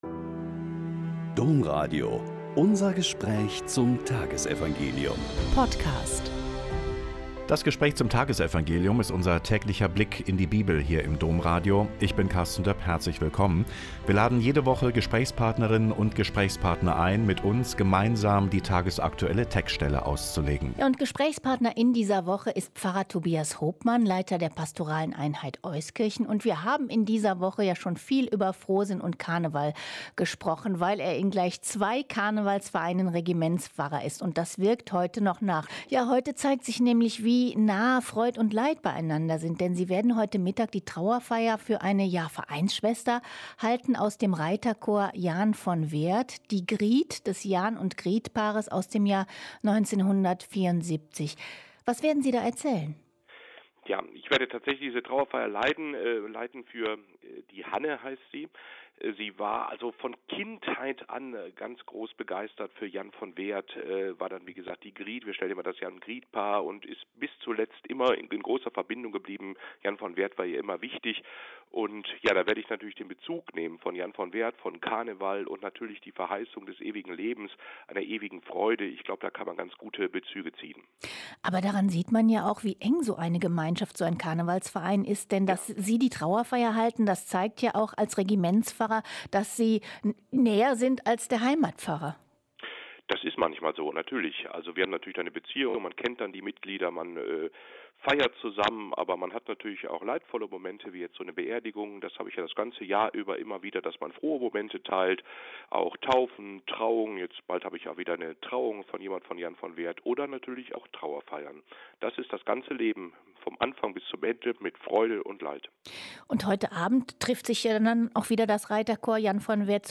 Lk 5,27-32 - Gespräch